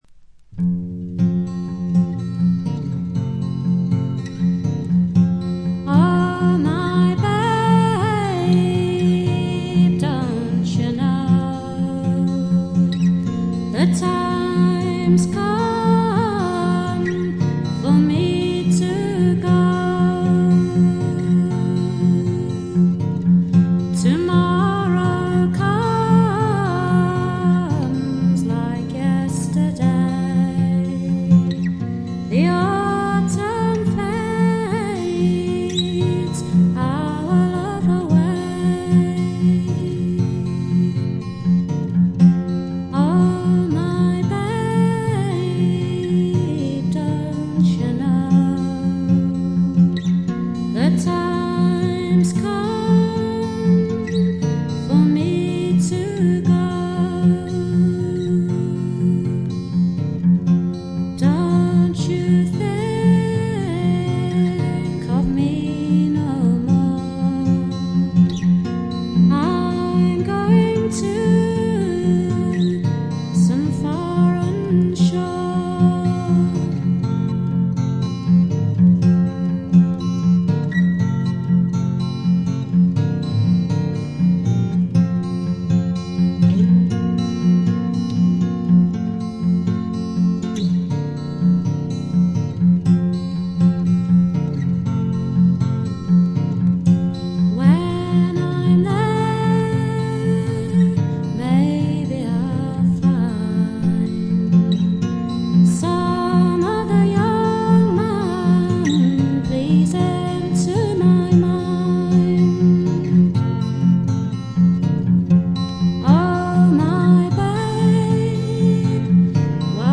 演奏面は彼女自身が奏でるギター、ブズーキだけと非常にシンプルなもの。
アルバム全体はしっとりした雰囲気で、不思議な浮遊感があり少しくぐもったかわいい歌声が「夢の世界」を彷徨させてくれます。
試聴曲は現品からの取り込み音源です。